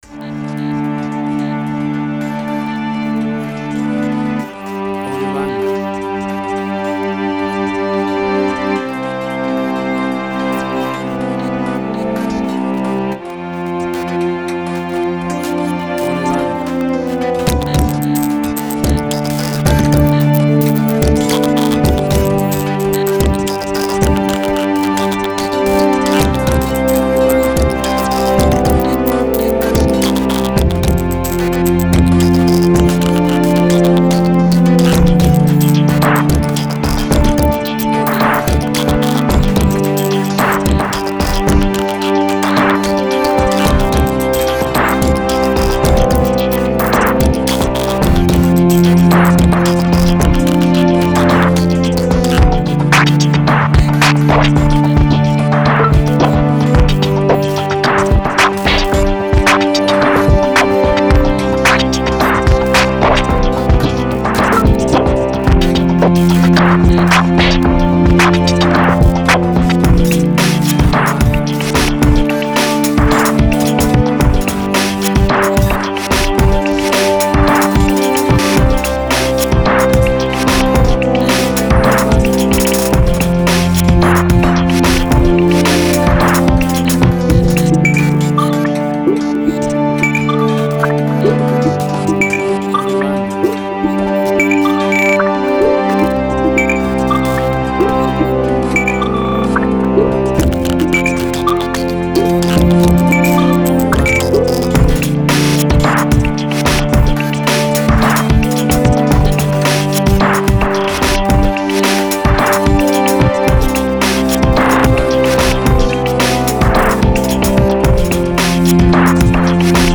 IDM, Glitch.
Tempo (BPM): 110